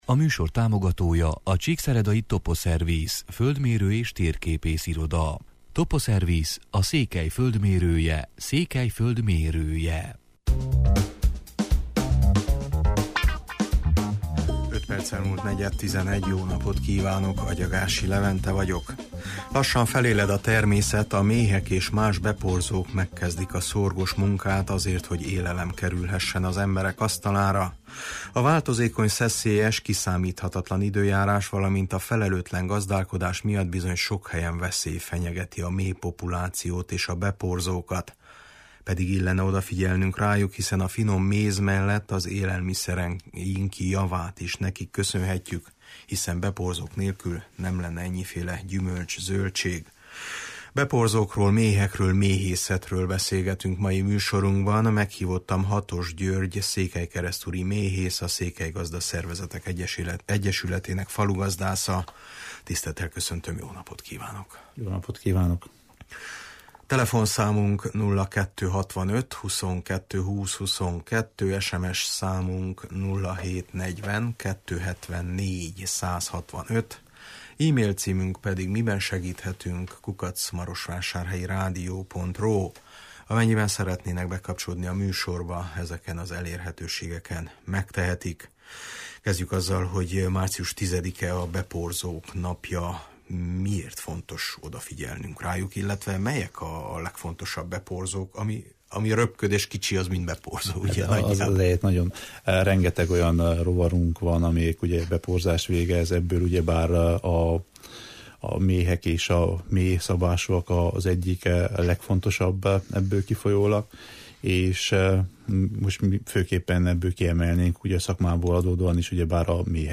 A beporzókról, méhekről, méhészetről beszélgetünk mai műsorunkban.